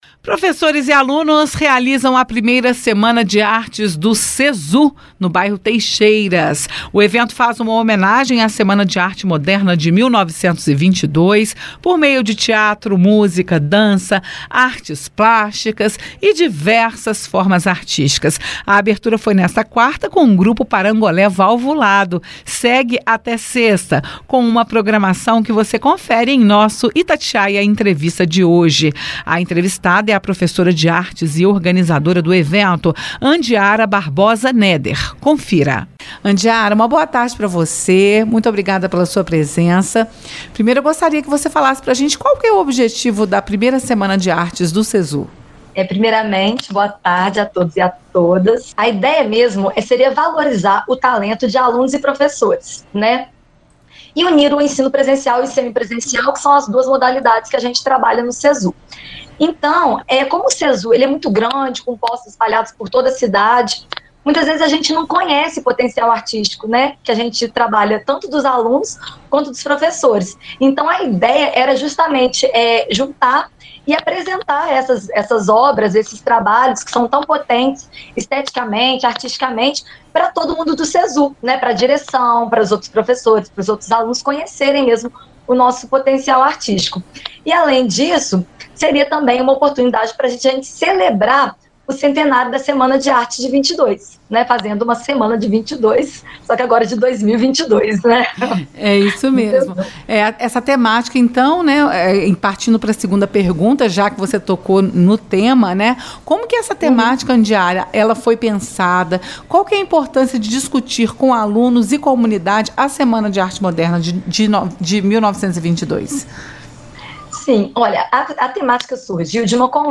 SEMANA-DE-ARTE-DO-CESU-Itatiaia-Entrevista_Semana-CESU.mp3